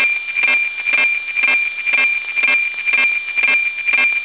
Satellites bande 137 MHz